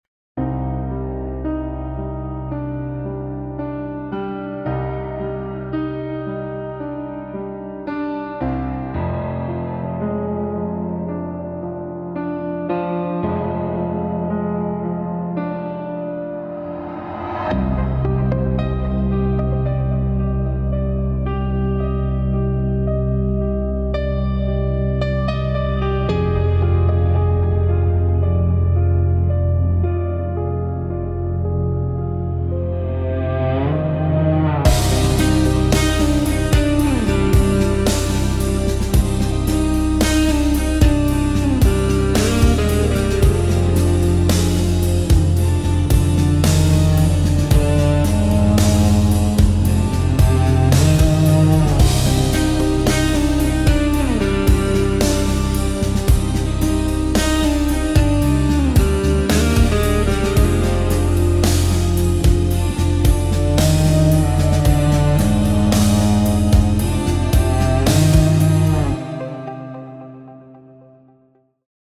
This track represents the C Aeolian Mode.